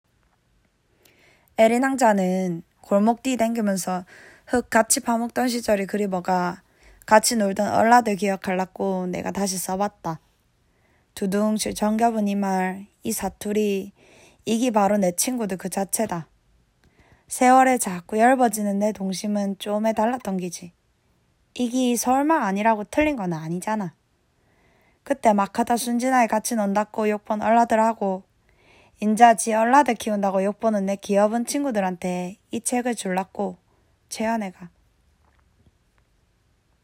독서모임을 해서 가장 사투리를 구수하게 읽은 한 멤버가 직접 낭독해주는 파일을 들어보세요!
갱상도 사투리로 들으니 아주 구수하죠?